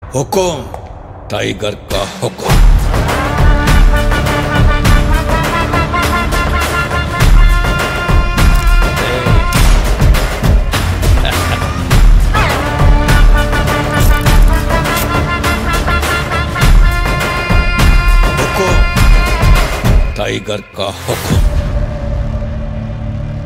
It gives a stylish and powerful ringtone feel.